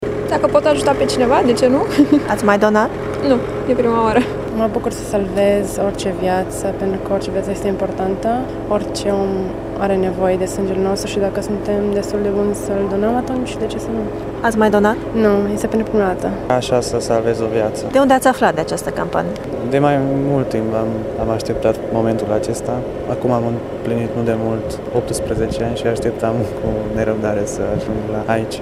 Astăzi de dimineață, în fața centrului mobil s-au așezat la rând câțiva tineri, mulți dintre ei la prima donare, ghidați de ideea că fiecare picătură de sânge poate salva o viață: